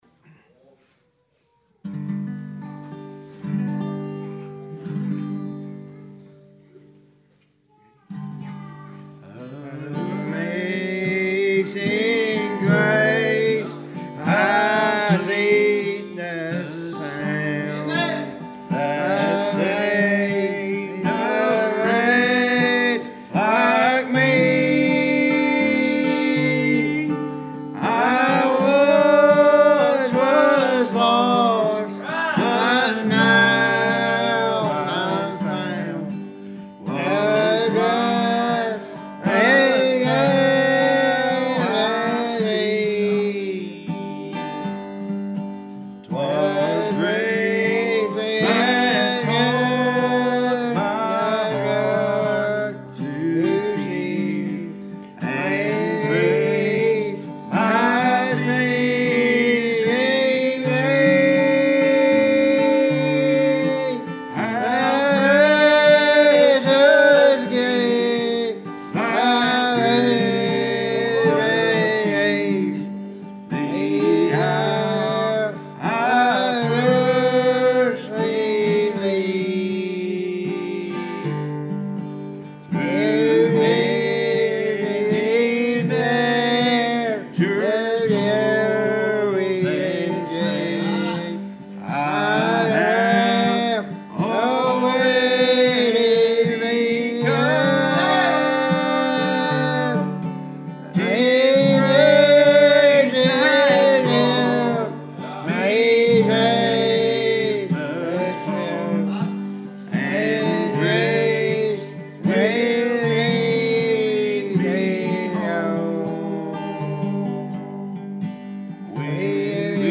Passage: Psalm 104:31-35 Service Type: Wednesday Evening